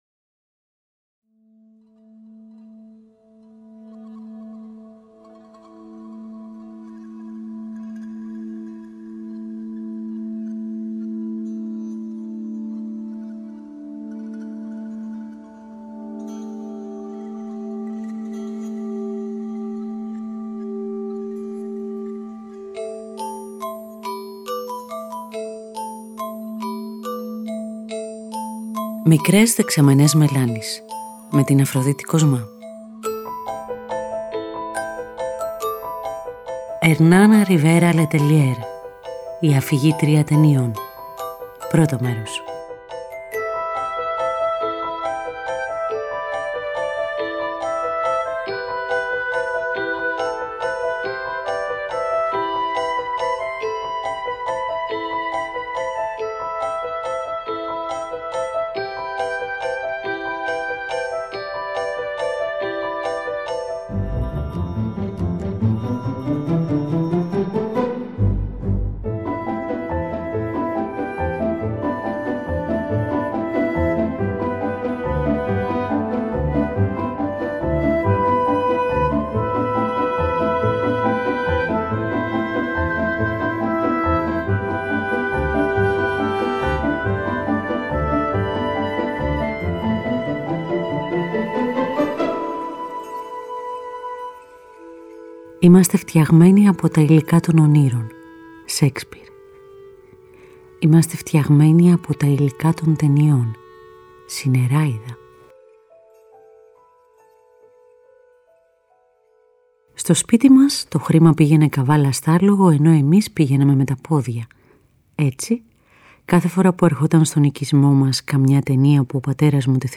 Μια ραδιοφωνική εκπομπή βουτηγμένη στη μαγεία των κινούμενων εικόνων και της μουσικής τους, στη φαντασία ενός κοριτσιού και στη σκληρή ζωή στο άνυδρο τοπίο της πάμπας.